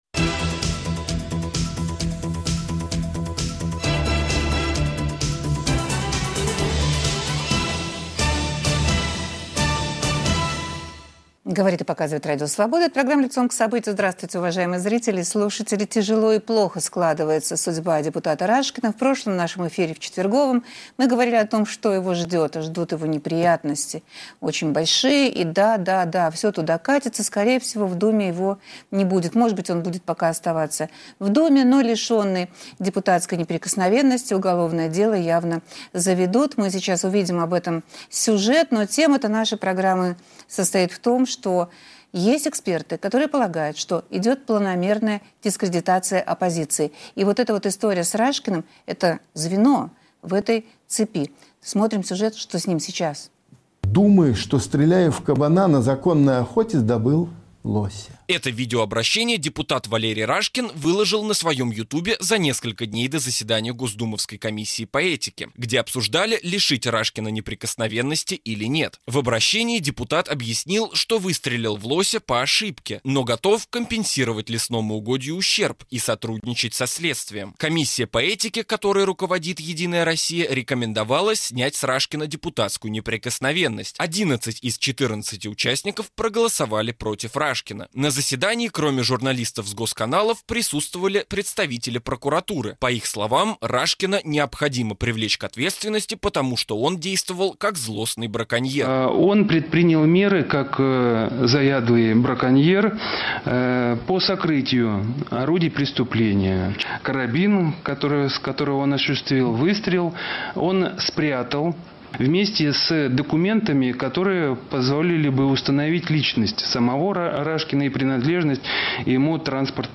О комплексе способов дискредитации оппозиции разговор с участием политолога Дмитрия Орешкина и политиков Константина Янкаускаса и Максима Шевченко.